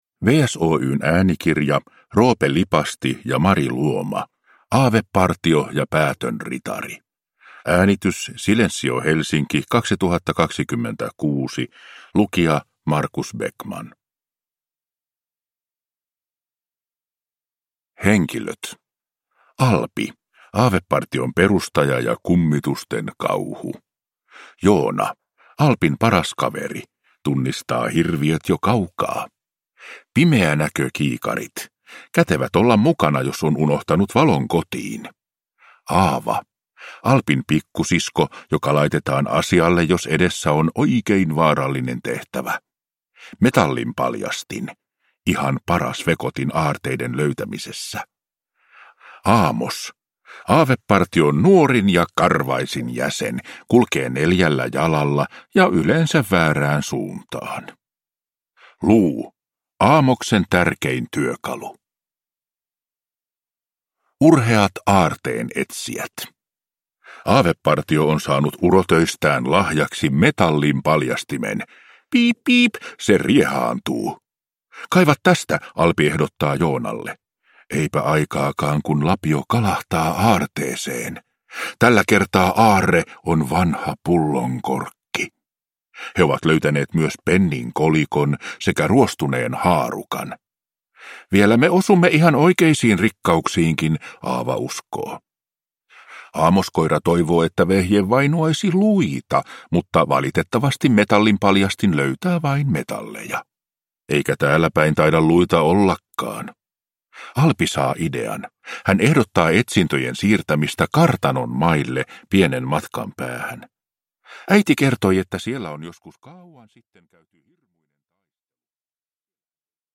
Aavepartio ja päätön ritari – Ljudbok